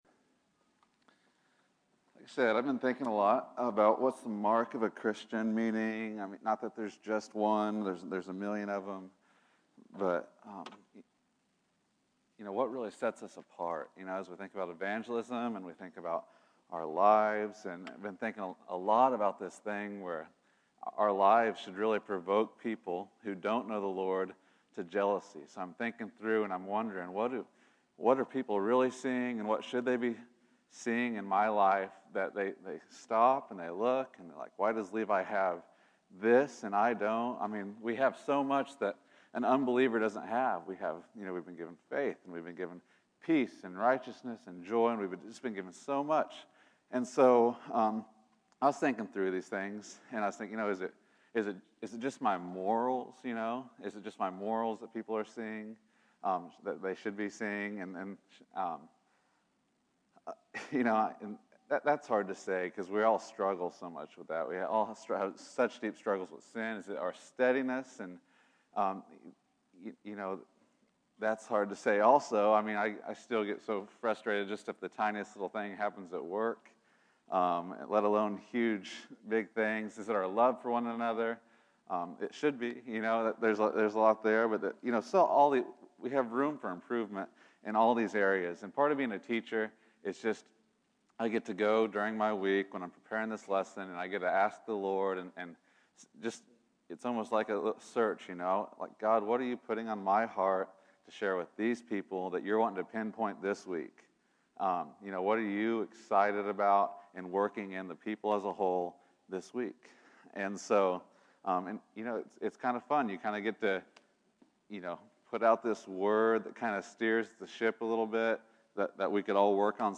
Contend for Joy September 06, 2015 Category: Sunday School | Location: El Dorado Back to the Resource Library The essential call for the believer to find joy in Christ.